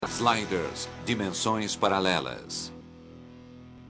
Ein Sprecher nennt den brasilianischen Titel.